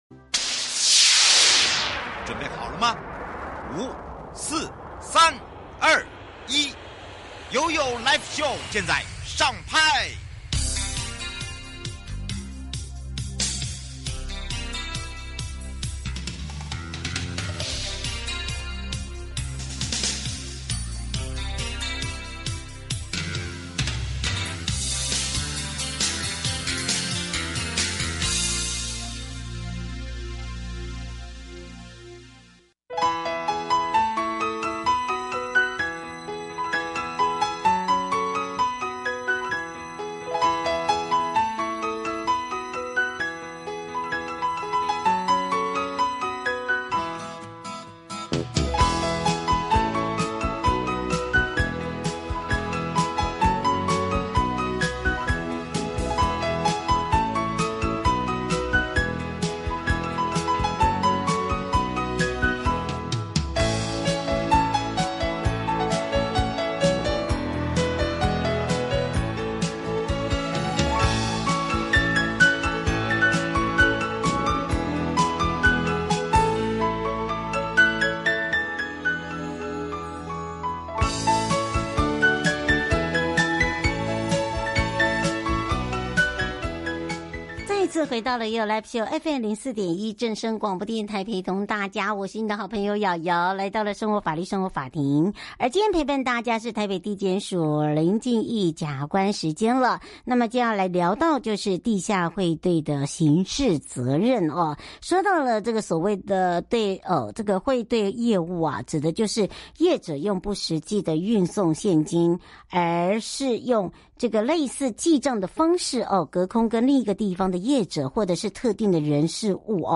受訪者： 1. 法務部徐錫祥政務次長 3.高檢署張斗輝檢察長 4.法務部鄭銘謙部長 節目內容： 1.國安、資安